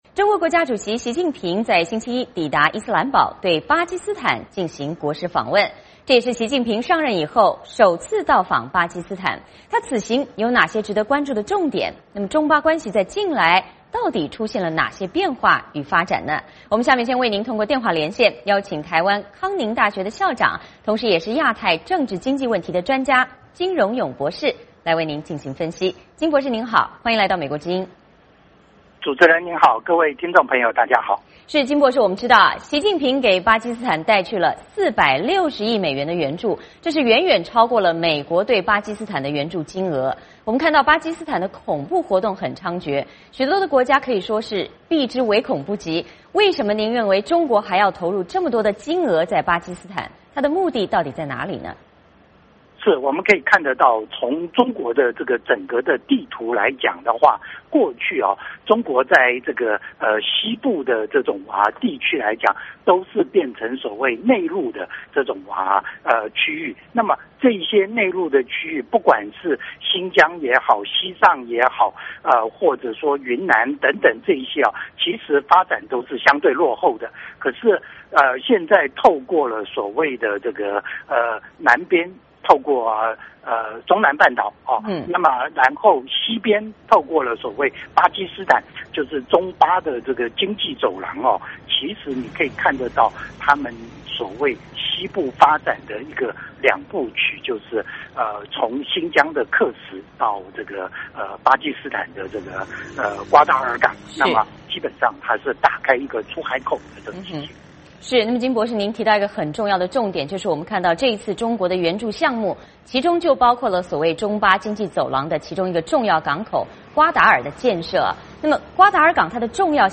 中国国家主席习近平星期一抵达伊斯兰堡，对巴基斯坦进行国事访问，这是习近平上任后首次到访巴基斯坦，他此行有哪些值得关注的重点?中巴关系近来出现哪些变化与发展?我们通过电话连线